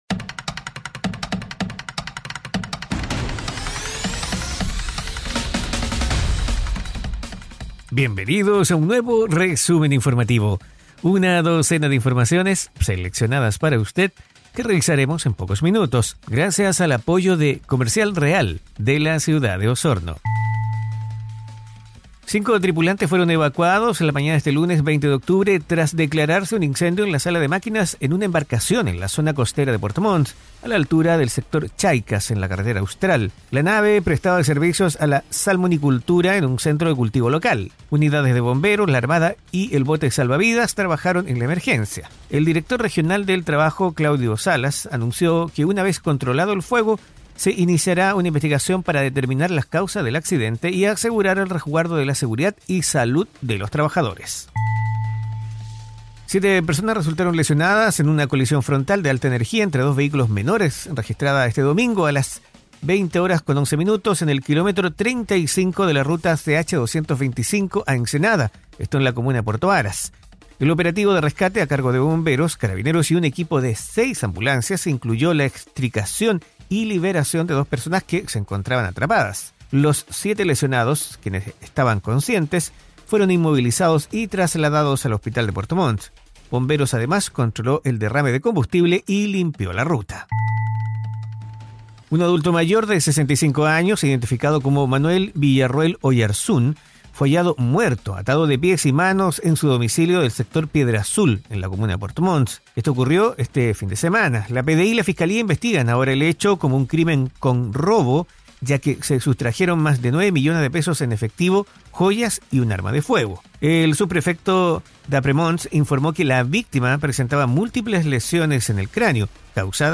Resumen Informativo 🎙 Podcast 20 de octubre de 2025